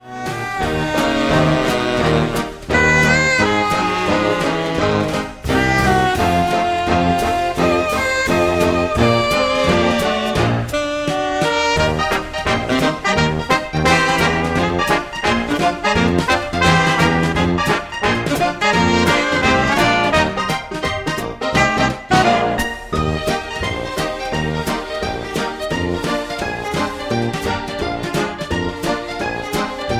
Eb
MPEG 1 Layer 3 (Stereo)
Backing track Karaoke
Pop, Oldies, Jazz/Big Band